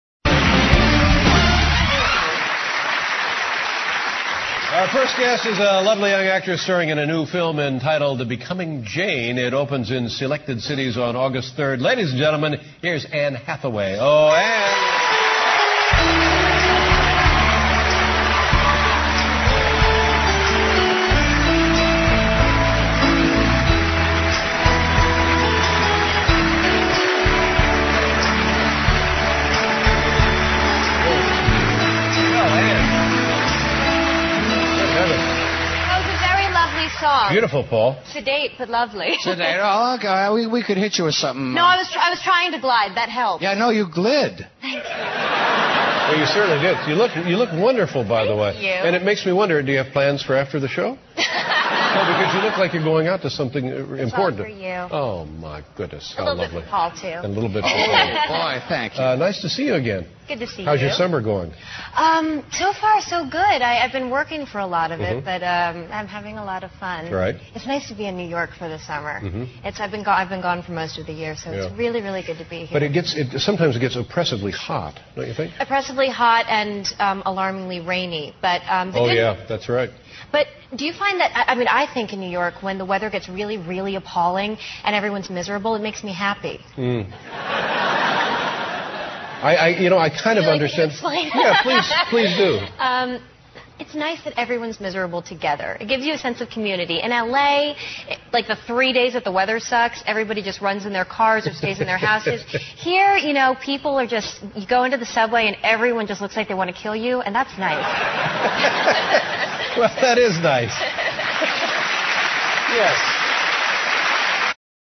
访谈录 Interview 2007-08-18&08-19, "成为简·奥斯汀"女主角亮相脱口秀（上） 听力文件下载—在线英语听力室